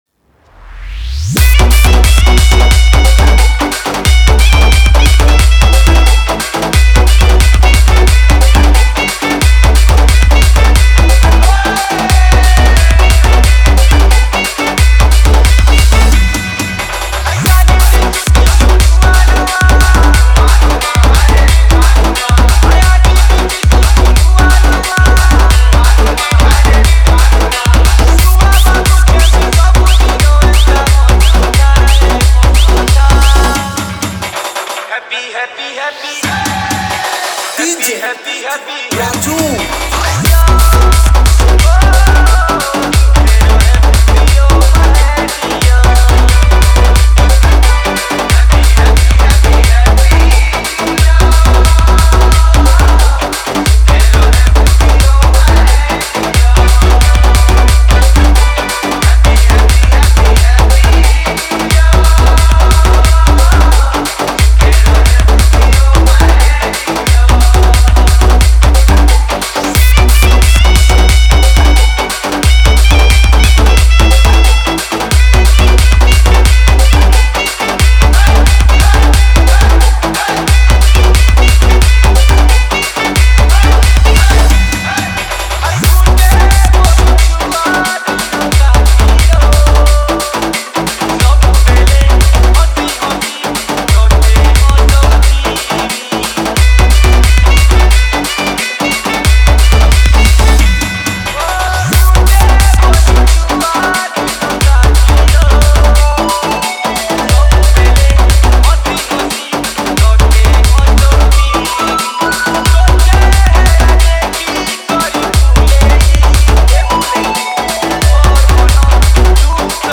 New Year Special Dj Remix